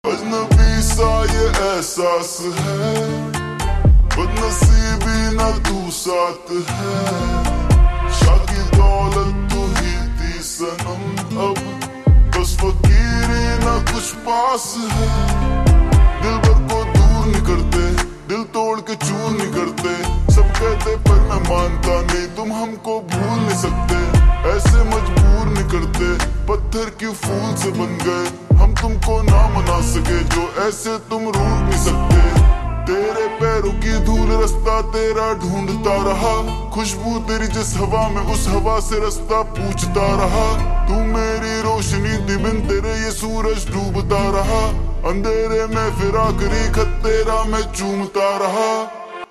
Hindi Songs
(Slowed + Reverb)